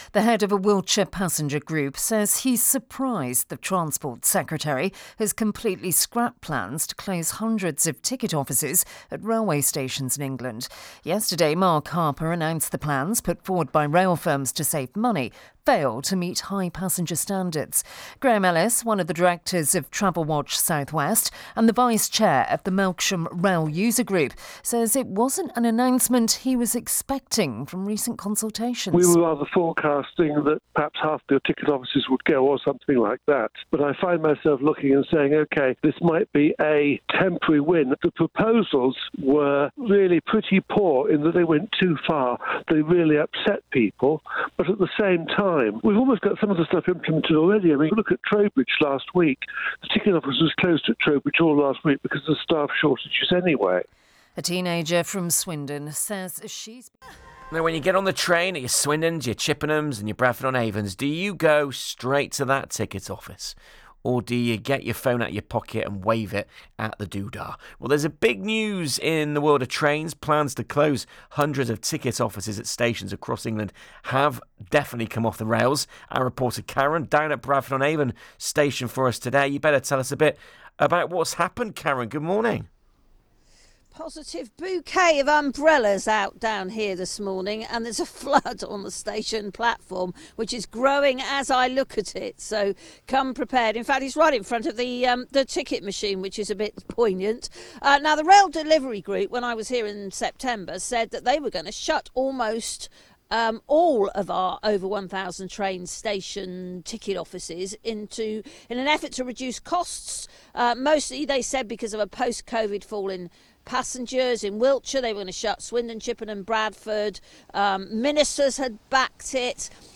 - audio clip of my radio interview on the topic